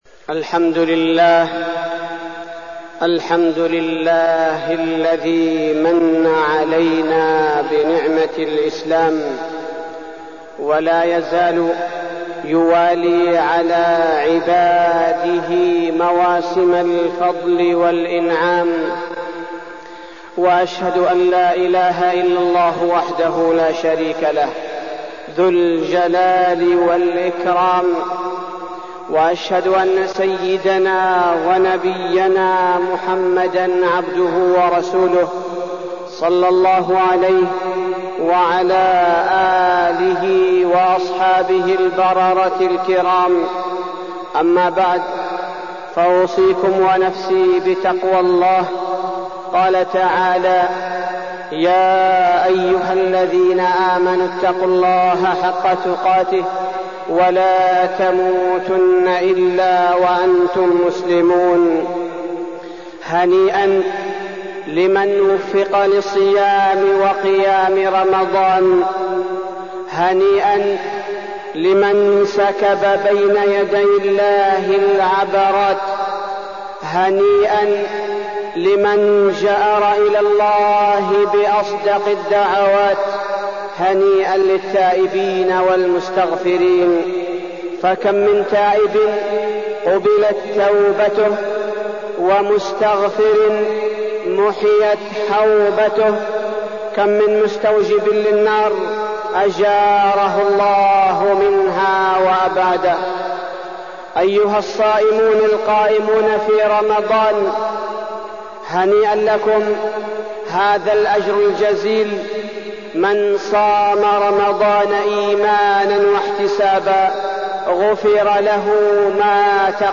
تاريخ النشر ٥ شوال ١٤١٩ هـ المكان: المسجد النبوي الشيخ: فضيلة الشيخ عبدالباري الثبيتي فضيلة الشيخ عبدالباري الثبيتي العمل الصالح